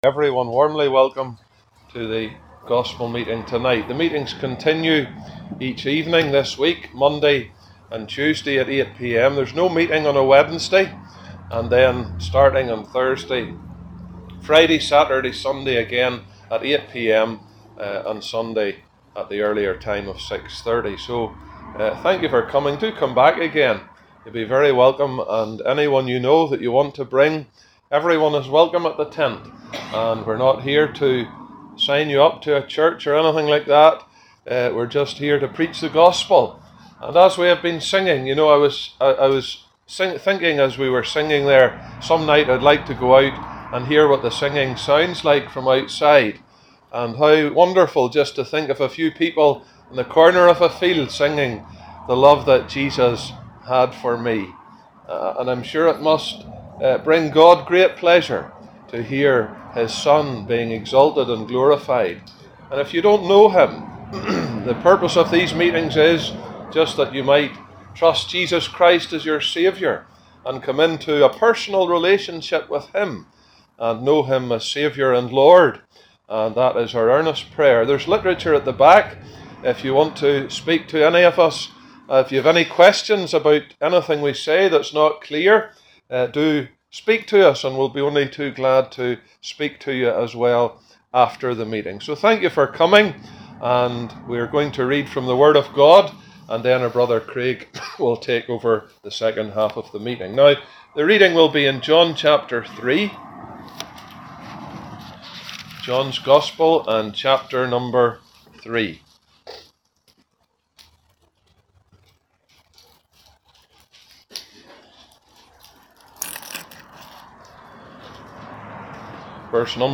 He expands on the Must, the Miracle and the Moment of the new birth. A simply clear and easy to listen gospel message.